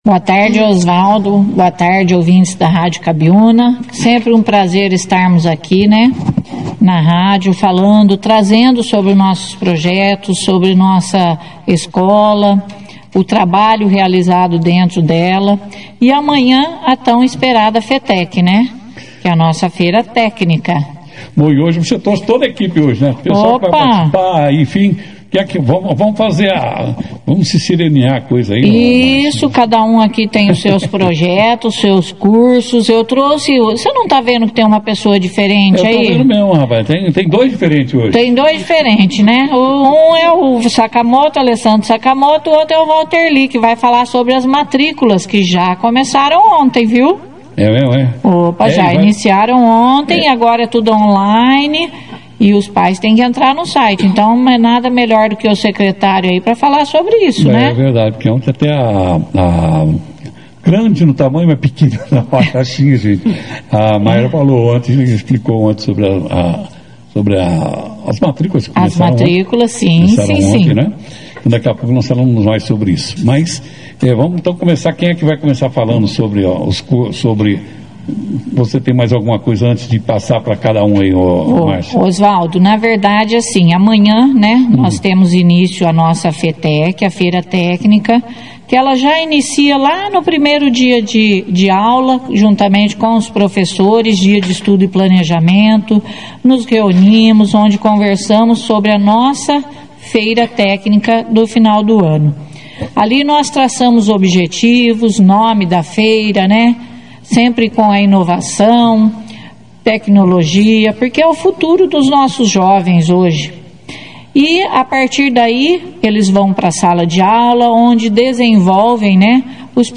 Durante a entrevista, foi apresentada a VIII Feira de Tecnologia do CEEP (Fetec), que será realizada nesta sexta-feira, 03 de outubro, nos períodos da manhã, das 8h às 11h30, e da noite, das 19h às 21h30.